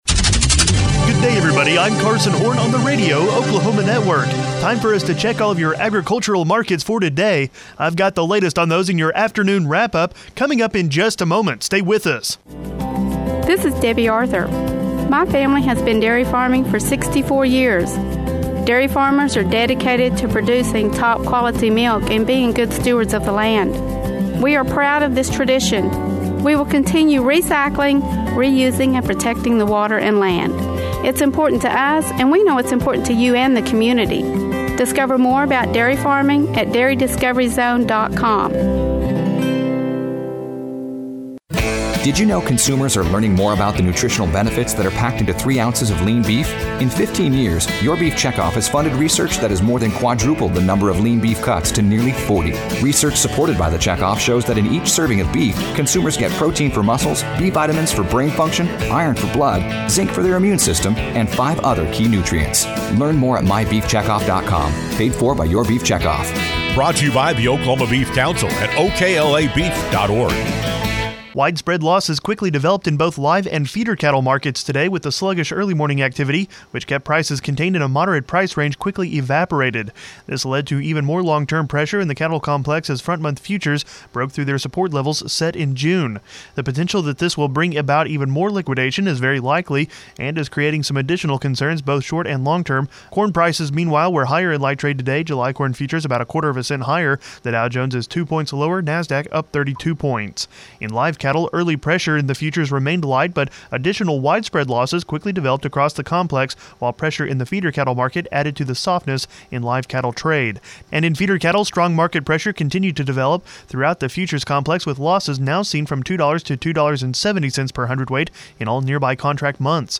Wednesday Afternoon Market Wrap-Up